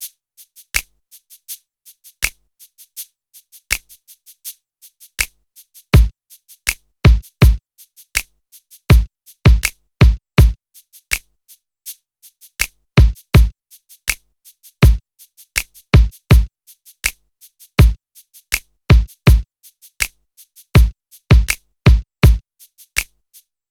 06 drums B.wav